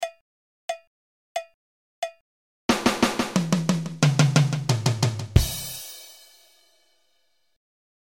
roulement sur les toms et la caisse claire
La première est jeu irrégulier ressemblant au galop d’un cheval boiteux.
La seconde est le deuxième coup de votre main faible moins fort que les autres.
roulement4tempsfaux2.mp3